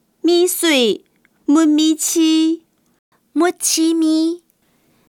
Hakka tts 用中文字典方式去mapping客語語音 客語語音來源 1.